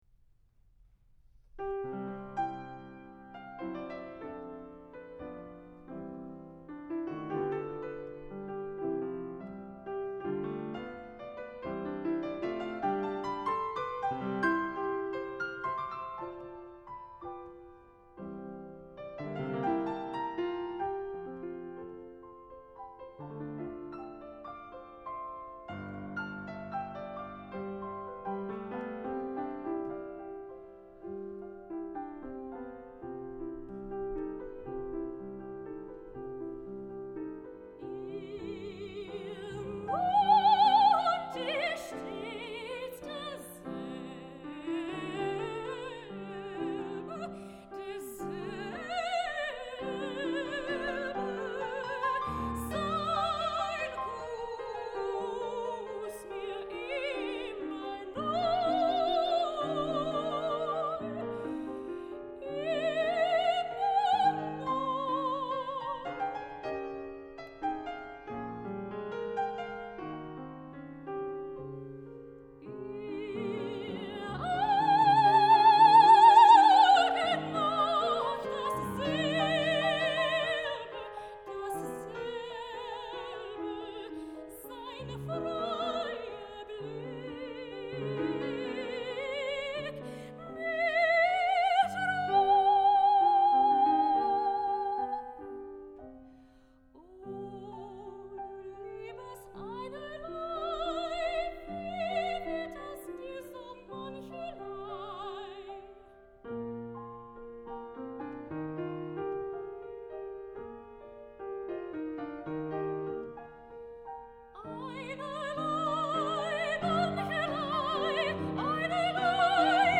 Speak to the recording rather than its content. Seiji Ozawa Hall at Tanglewood